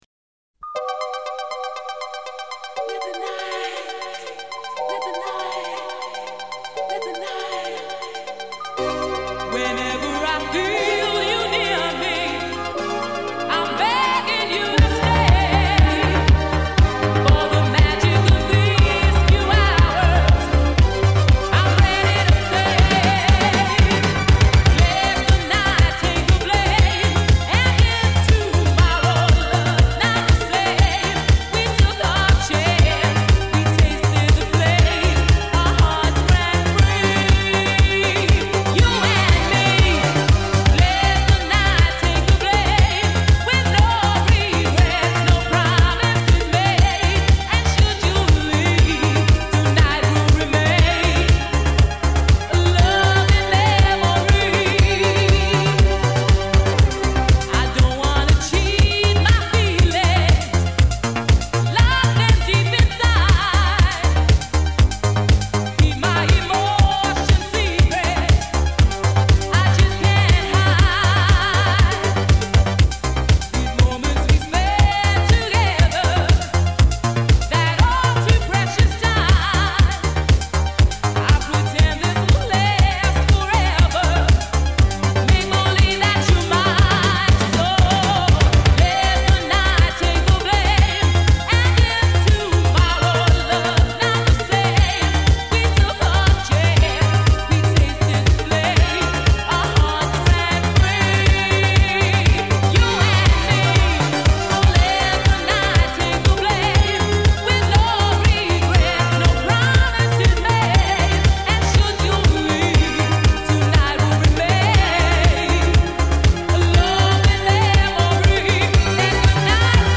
This is truly classic gay disco.